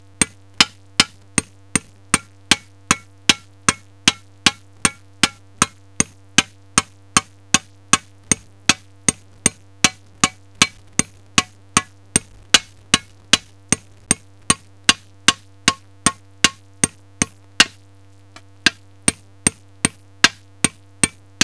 Klepajne
Z má dabrga pasluha se šliš, kadáj je klepač udáru u práznu n kadáj je udáru tak, de j uastríe šla gar na kasa. Usáka písm ima súj takt, zatú ima sajga tut písm uat klepajna; duákat u práznu, ajnkat u pouhnu.
klepajne1.wav